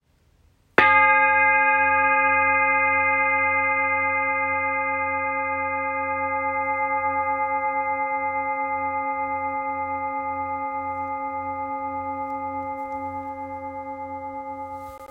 Large Etched Bowl with Seven Chakra Symbols Tibetan – 39cm
Rich and resonant, perfect for meditation, sound baths, and energy work.
Each bowl comes with a cushion and striker.
Tibetan-chakra-nada-stick.m4a